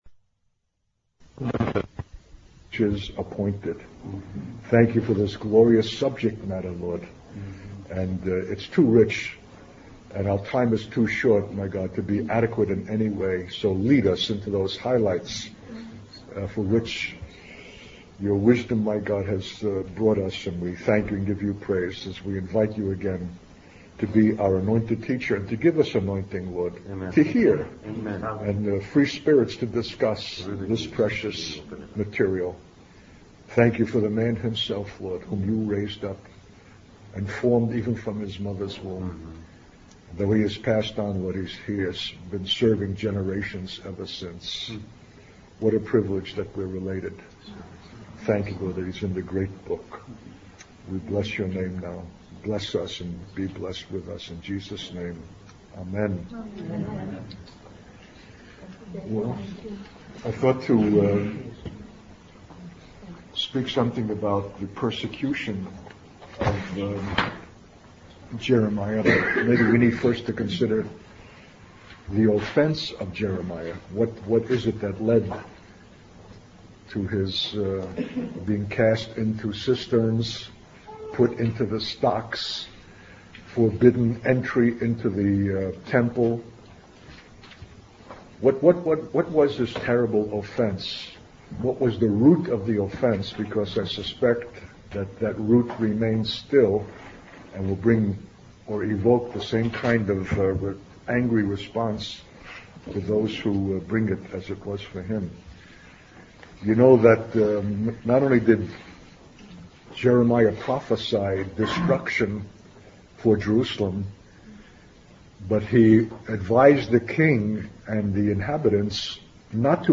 This wilderness represents a place of despair and complete dependence on God. The speaker highlights the danger of trusting in human strength and the importance of trusting in the Lord instead. The sermon also discusses the concept of a new covenant, where God's righteousness is deposited into the hearts of believers, enabling them to obey perfectly.